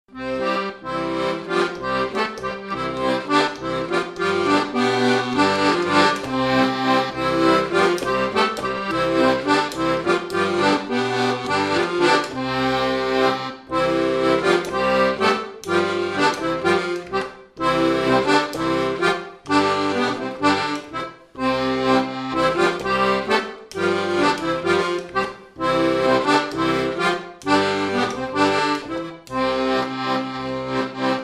Chavagnes-en-Paillers
Résumé instrumental
danse : polka-valse
Pièce musicale inédite